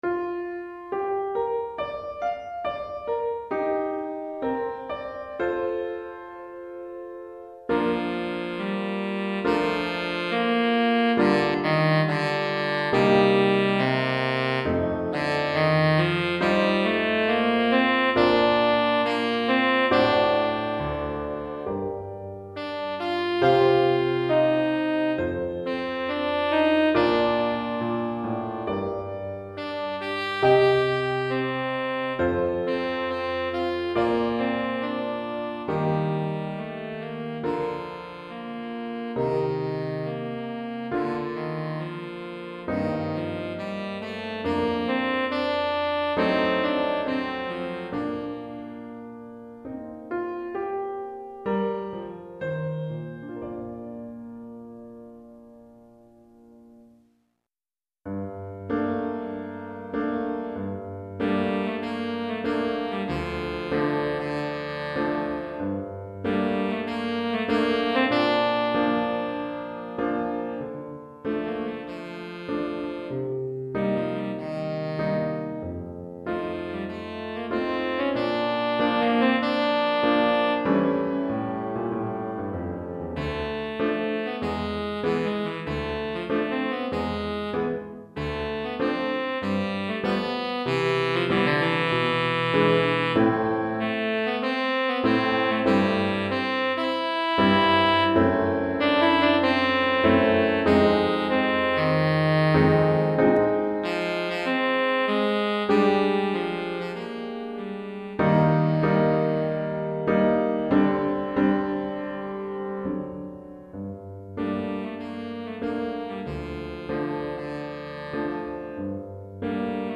Saxophone Soprano ou Ténor et Piano